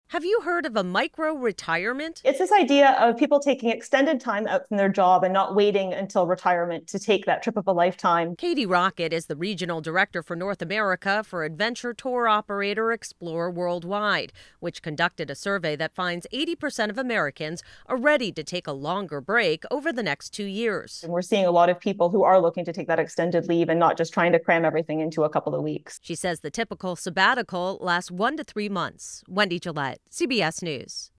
CBS Radio Interview Microretirement
CBS-Radio-Interview-Microretirement.mp3